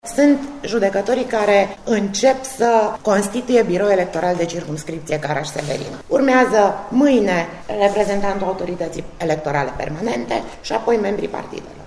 La rândul său, purtătorul de cuvânt al Tribunalului, judecătorul Daniela Deteşan, a precizat că biroul astfel constituit va îndeplini toate atribuţiile ce îi revin potrivit legii, urmând a fi completat, în alte 24 de ore, cu reprezentantul Autorităţii Electorale Permanente.